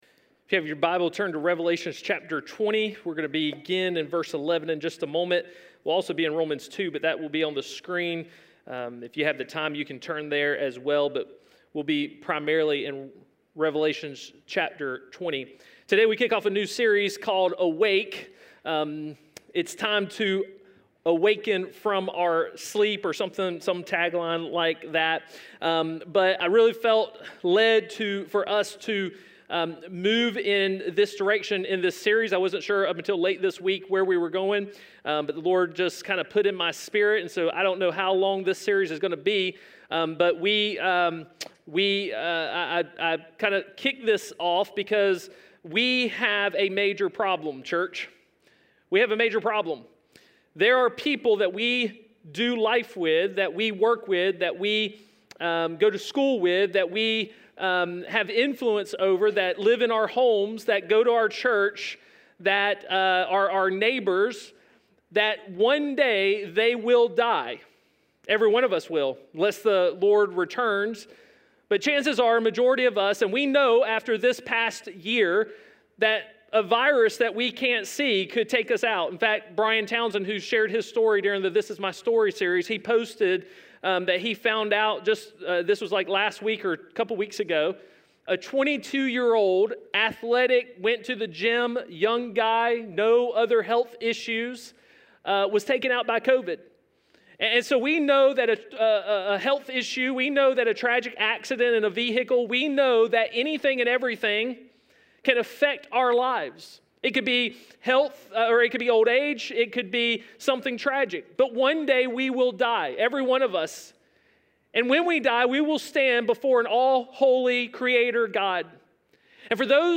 A message from the series "Unhindered."